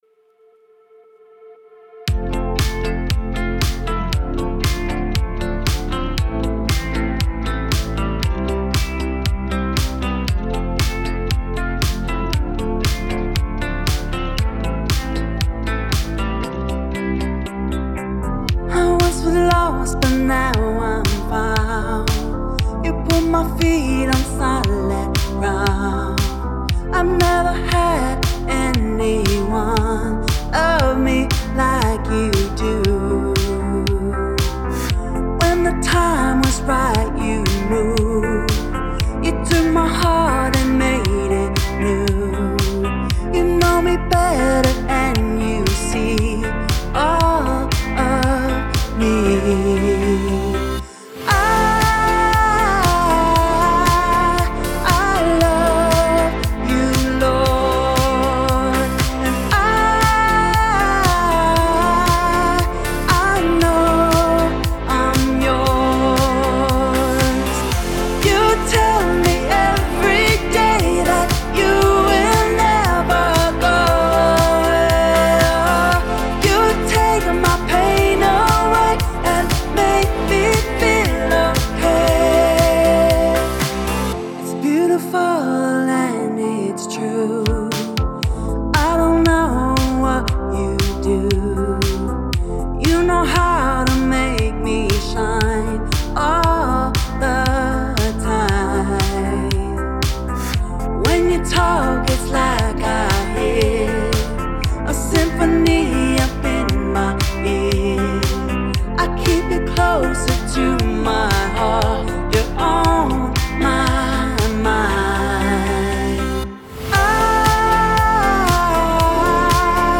An upbeat love song to the Lord!